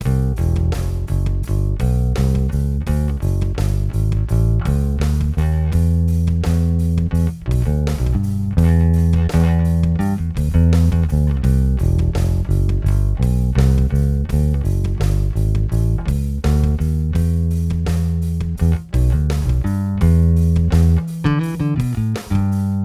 Ich hab mal die Aufnahmen als mp3 gerendert, die hier mit Tonpoti voll auf.